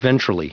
Prononciation du mot : ventrally